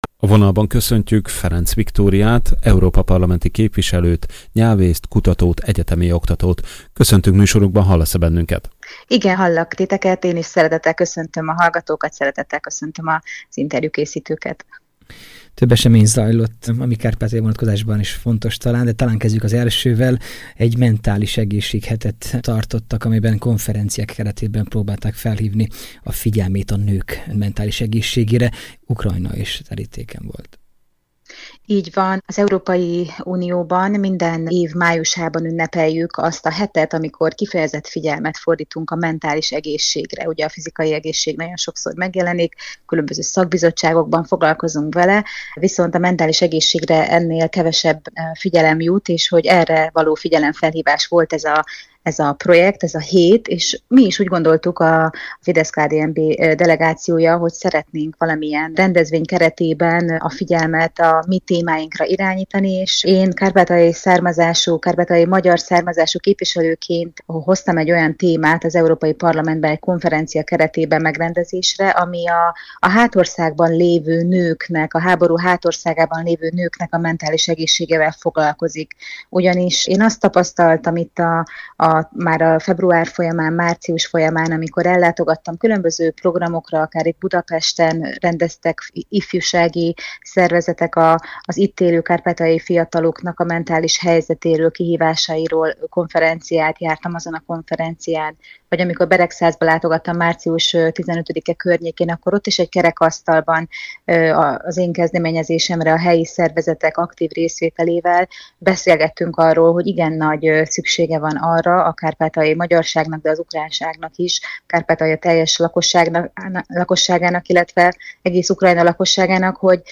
Mai Különkiadás c. műsorunkban Ferenc Viktória EP képviselővel, nyelvésszel, kutatóval, egyetemi oktatóval beszélgettünk.